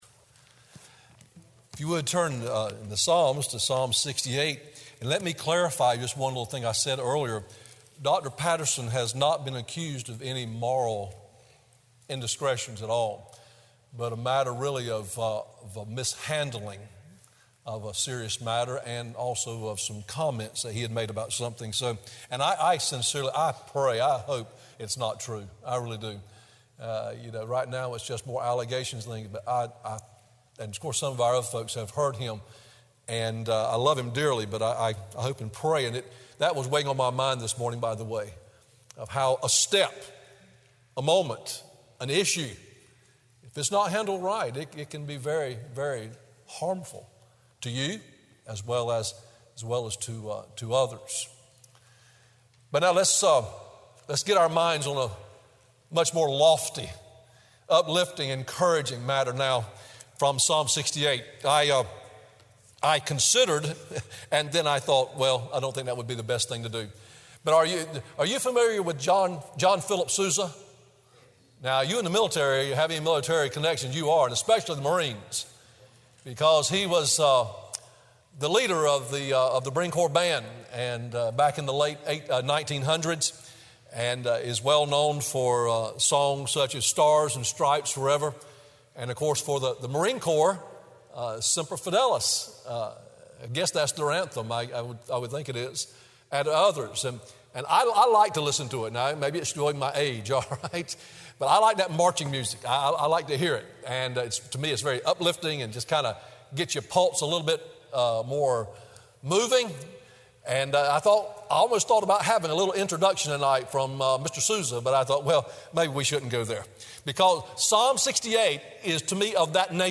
Sermon Audios/Videos - Tar Landing Baptist Church
Evening WorshipPhilippians 2:12-18